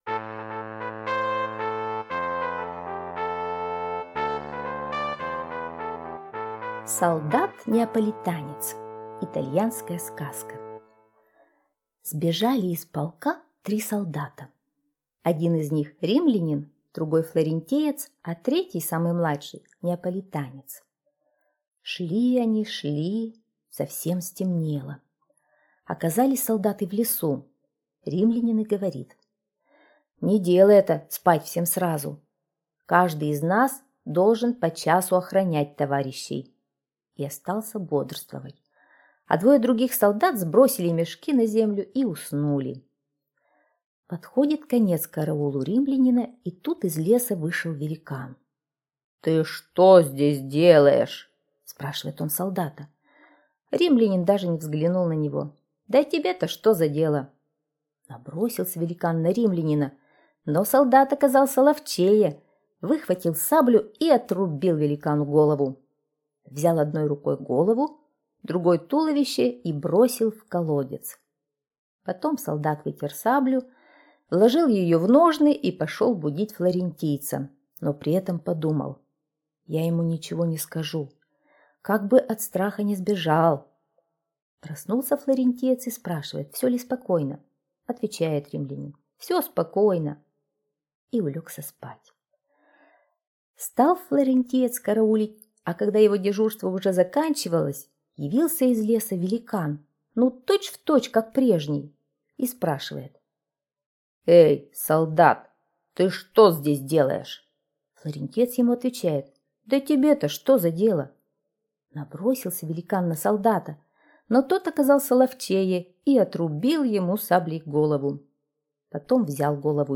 Солдат-неаполитанец - итальянская аудиосказка - слушать онлайн